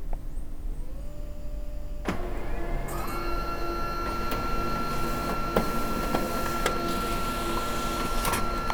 製品の稼動中に以下のファイルの音が発生しますが、正常な稼動音です。
・印刷中